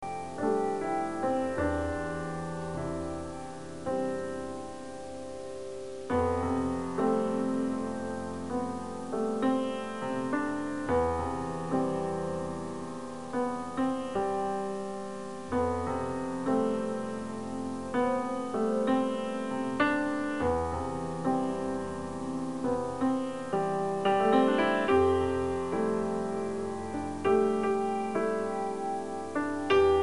sassofoni, clarinetto
pianoforte, elettronica
contrabbasso
batteria, elettronica
tromba, elettronica